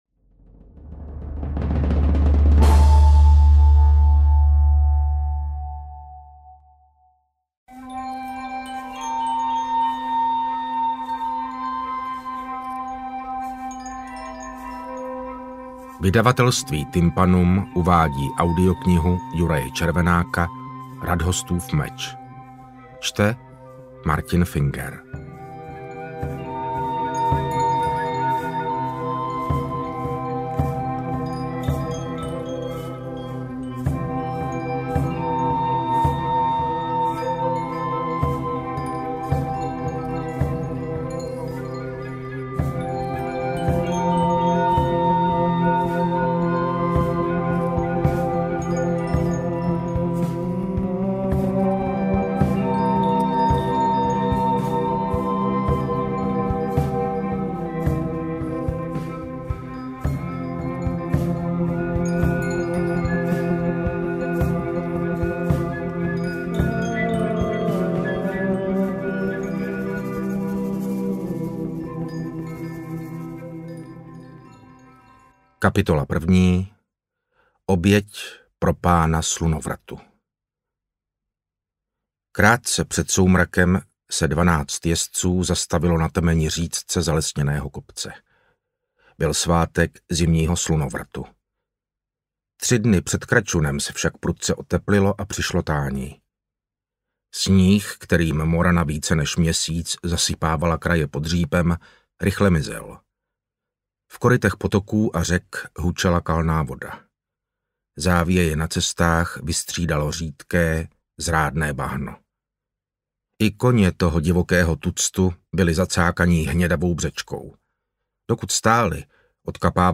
Interpret:  Martin Finger